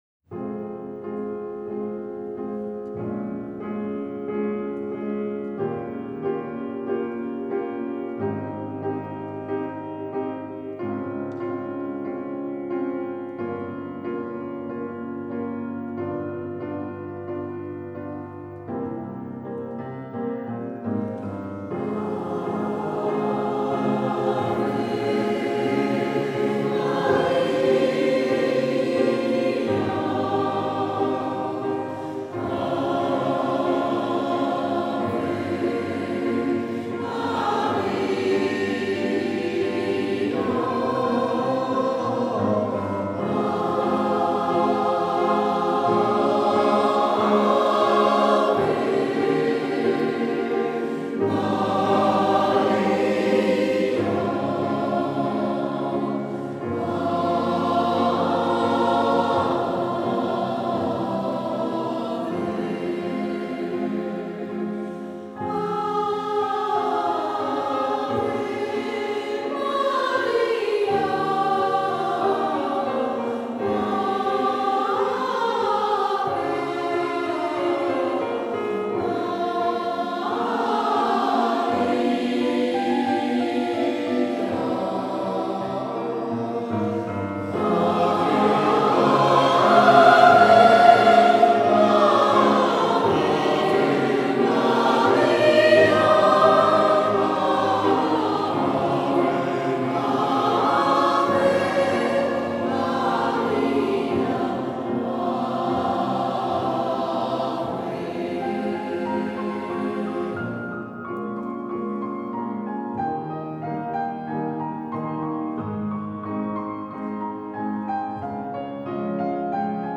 • Ave Maria (dit de "Caccini"), avec une interprétation de Tutti Canti à Mitry-Mory en 2006 :